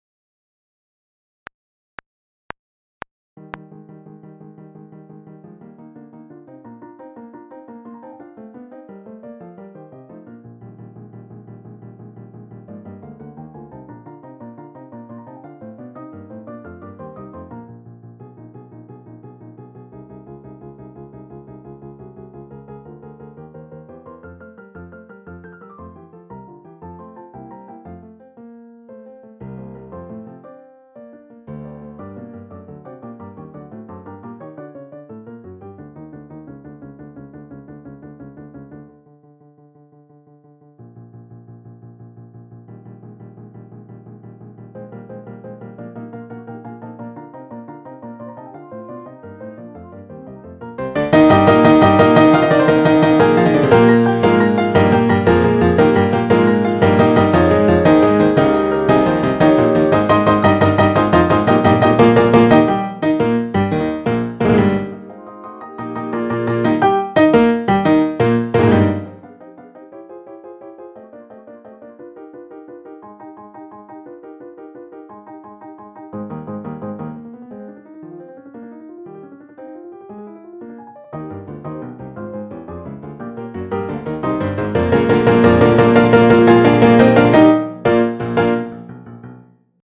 MIDI Practice tracks:
Dotted Half = 110